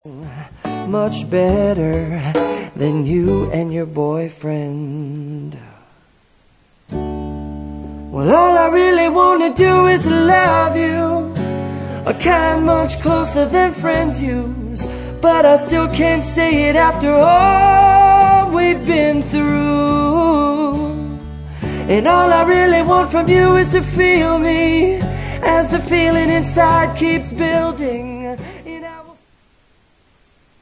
• Pop Ringtones